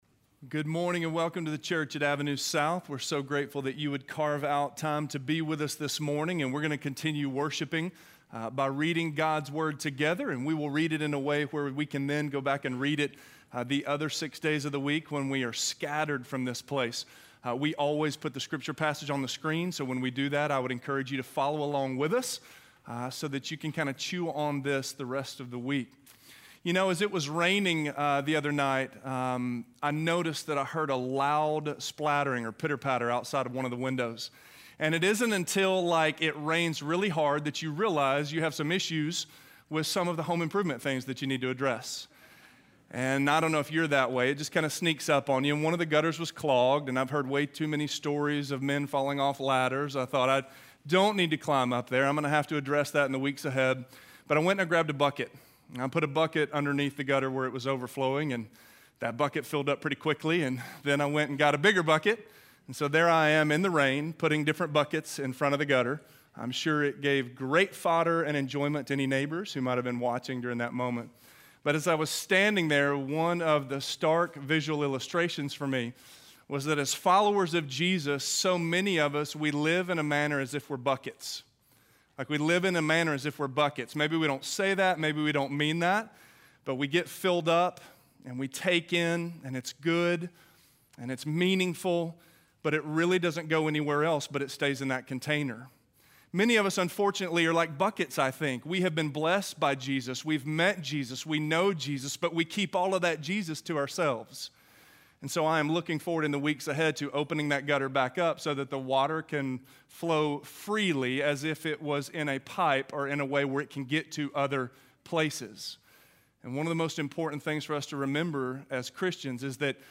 Your Story: Get Up and Walk - Sermon - Avenue South